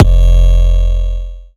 Index of /Antidote Advent/Drums - 808 Kicks
808 Kicks 14 F.wav